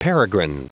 Prononciation du mot peregrine en anglais (fichier audio)
Prononciation du mot : peregrine